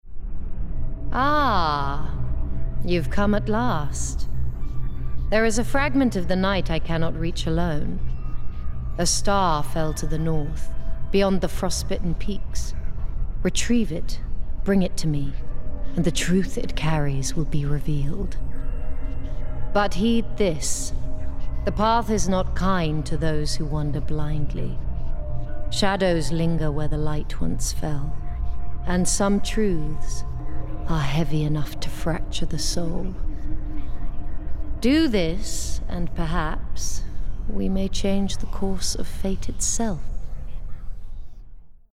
20/30's Neutral/RP,
Husky/Natural/Engaging
Mysterious Quest Giver (RP)